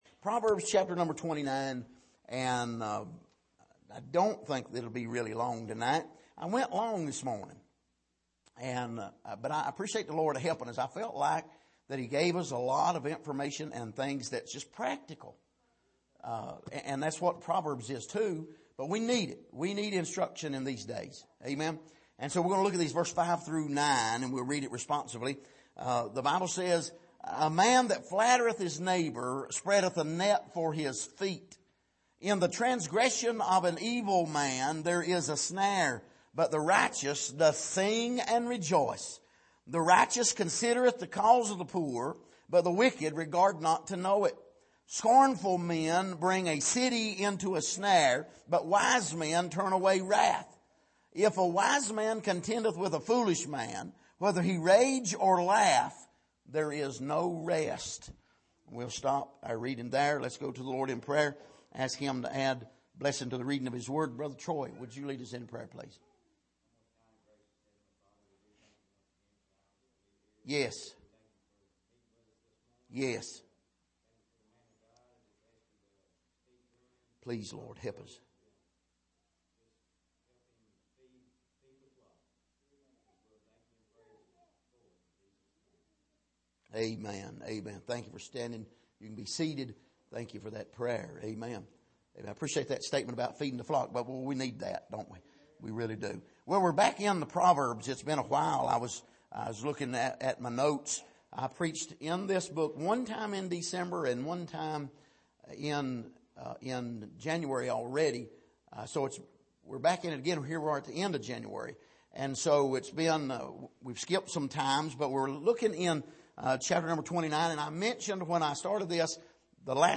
Passage: Proverbs 29:5-9 Service: Sunday Evening